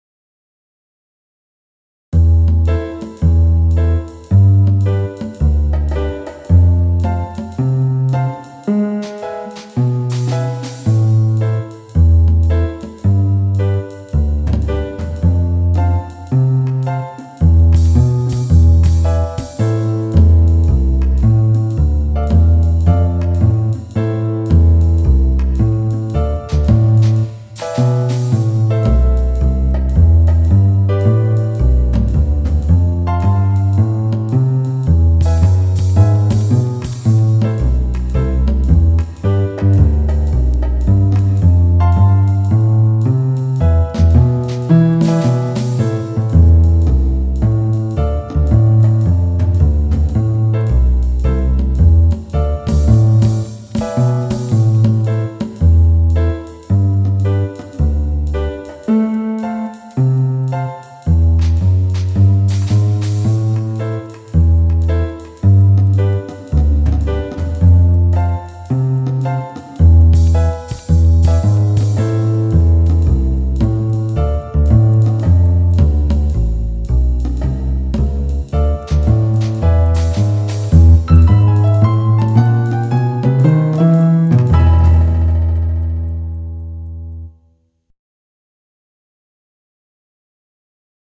5-jazzblues.mp3